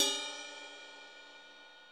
ride.wav